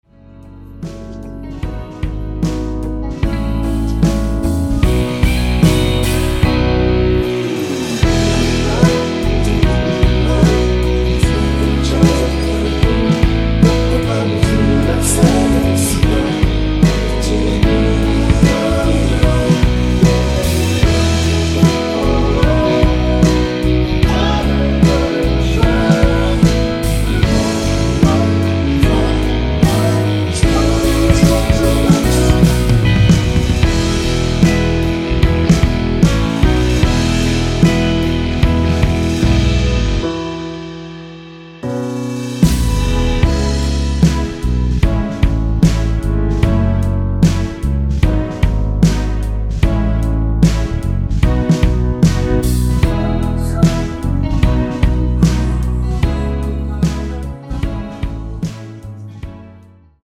원키에서(-2)내린 코러스 포함된 MR입니다.
앨범 | O.S.T
앞부분30초, 뒷부분30초씩 편집해서 올려 드리고 있습니다.
중간에 음이 끈어지고 다시 나오는 이유는